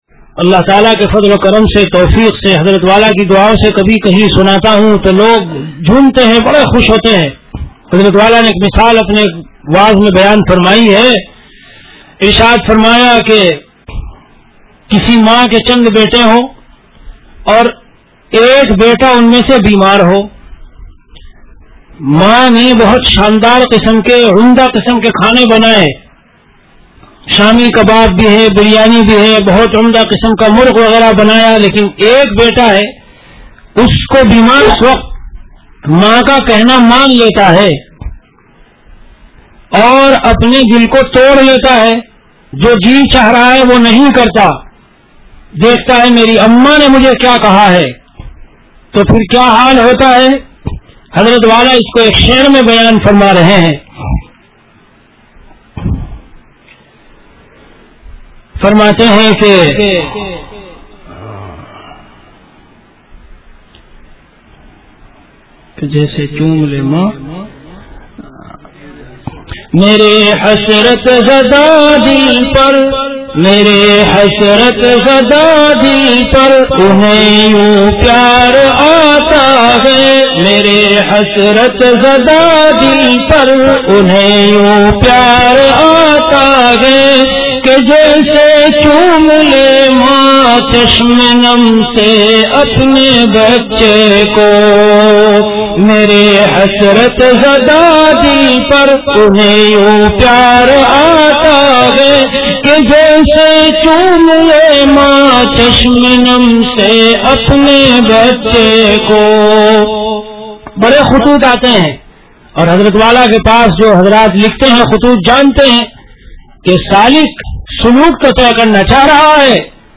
Delivered at Khanqah Imdadia Ashrafia.
Bayanat · Khanqah Imdadia Ashrafia